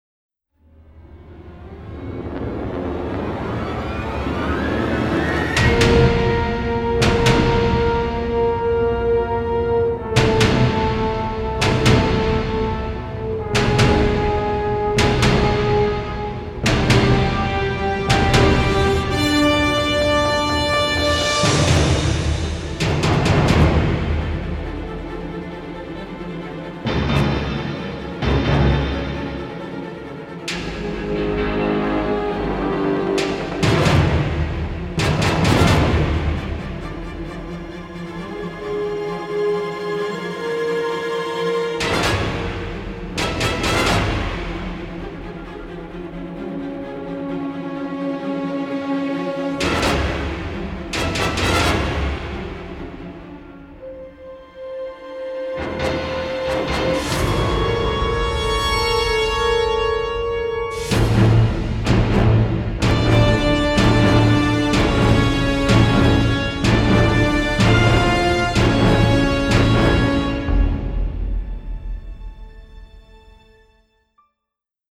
hip-hop
to English electronica
synthesizers, orchestra and choir
an unusually subtle yet powerful and dramatic score.